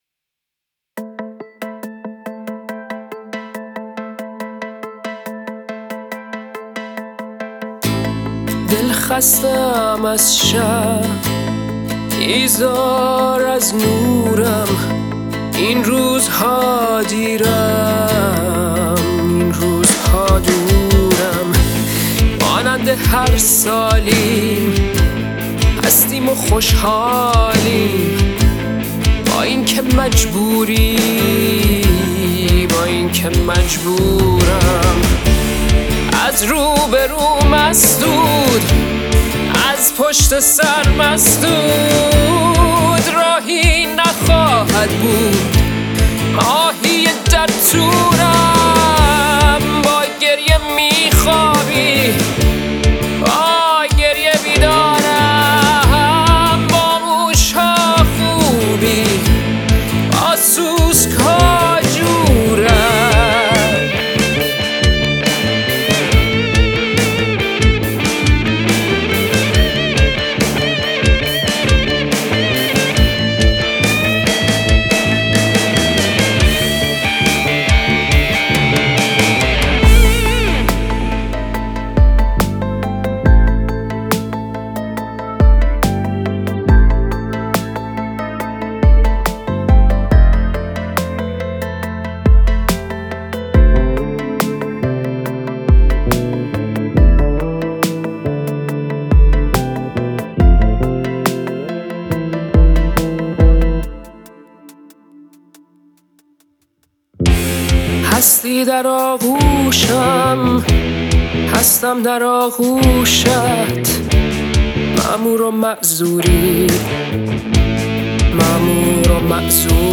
گیتار باس